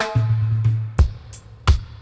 88-FILL-FX.wav